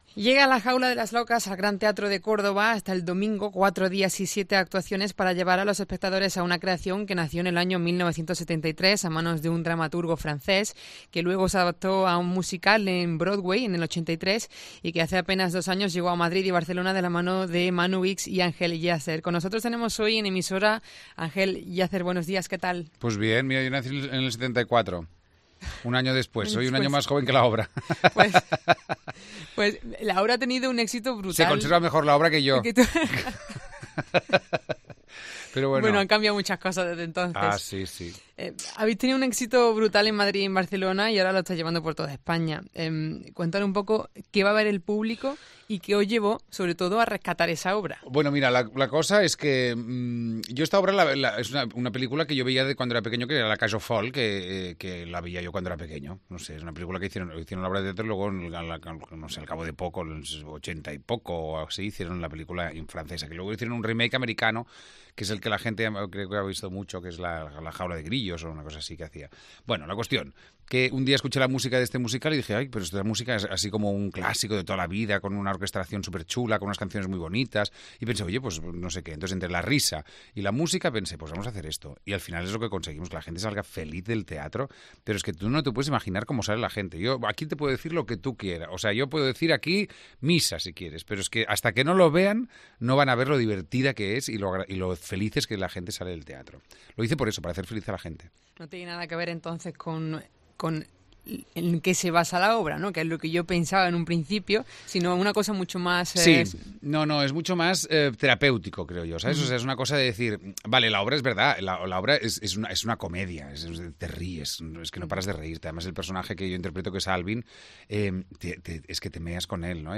Hoy hemos conversado con uno de sus directores, y protagonista, Ángel Yácer.